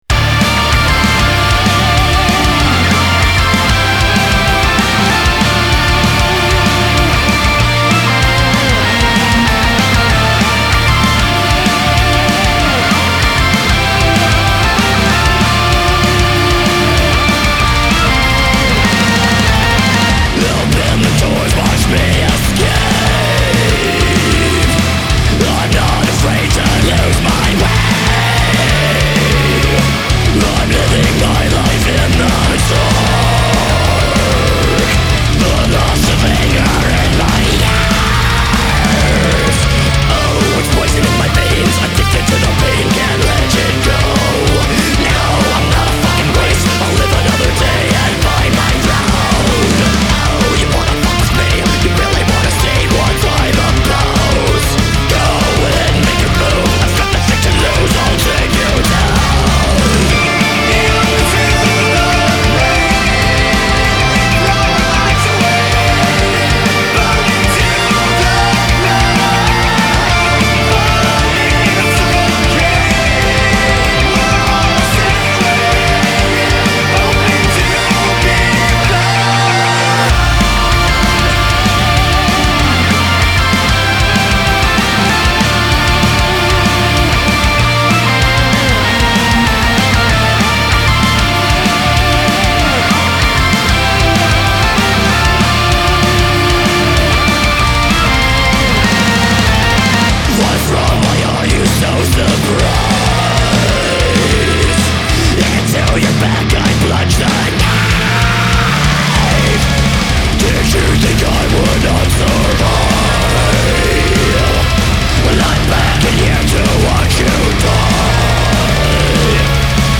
Genre: Metal.